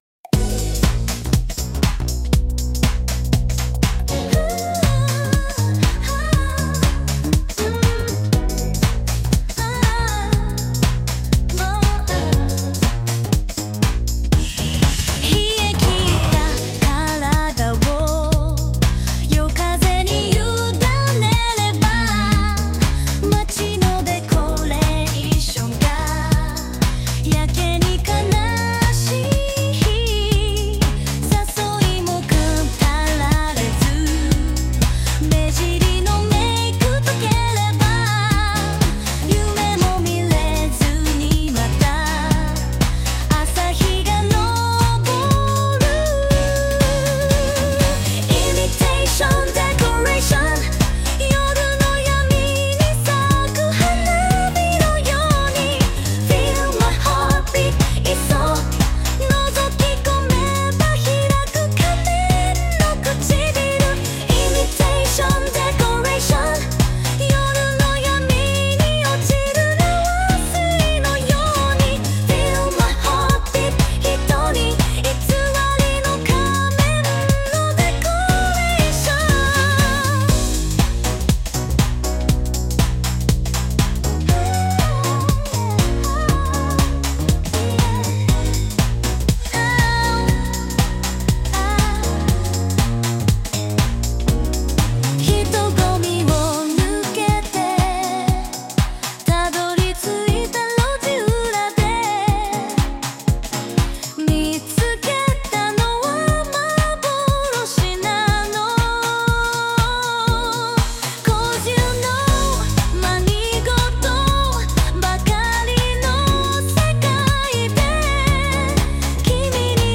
R&Bの旋律にハウス系のビートに乗せた感じの楽曲になりました。
カッコよく聞こえるのはハウスとかテクノなどダンス系のリズムを多用しているからだと思います。
で、延長部分にピアノソロパートを入れたり、ダイナミックなアウトロを入れたりしています。だからこの曲もラスト１分がめちゃくちゃ盛り上がってるんですよね。